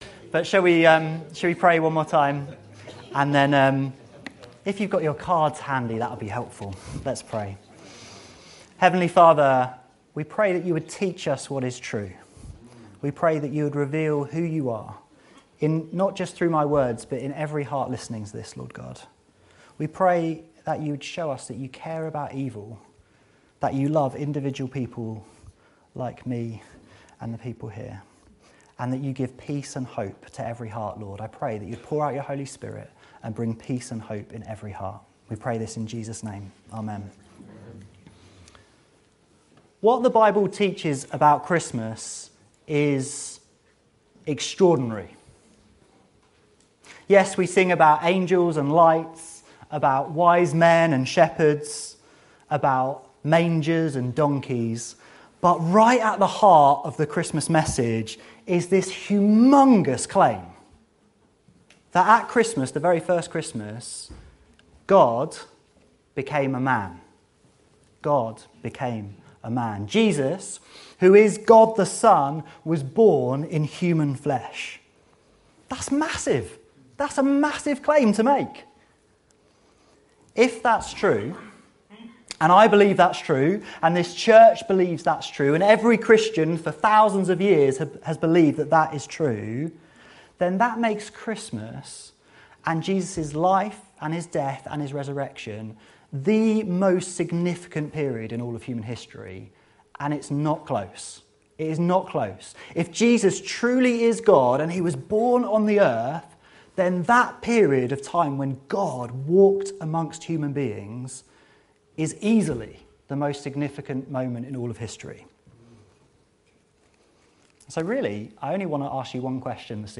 Christmas Themed Sermons